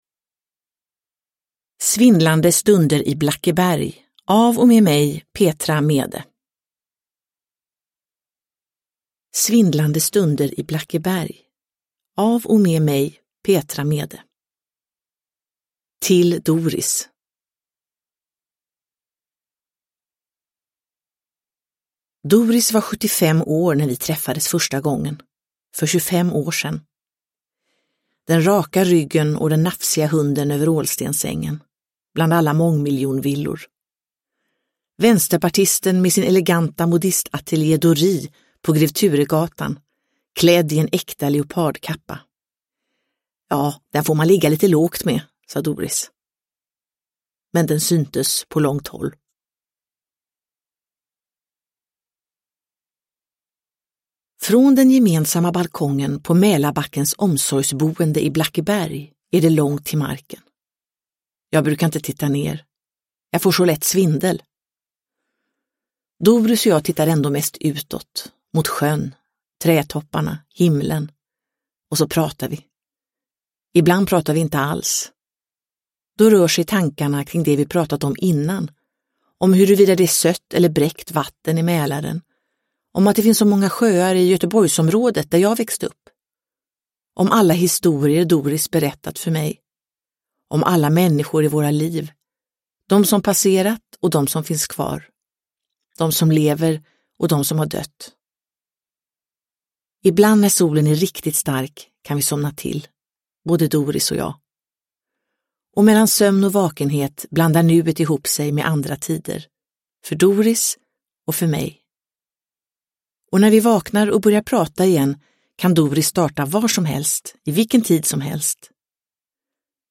Svindlande stunder i Blackeberg – Ljudbok – Laddas ner
Uppläsare: Petra Mede